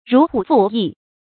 如虎傅翼 注音： ㄖㄨˊ ㄏㄨˇ ㄈㄨˋ ㄧˋ 讀音讀法： 意思解釋： 好象老虎生出翅膀。